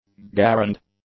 gairend, original French slur sounds like guron, universally called M1 ga-rand.
garand_english.mp3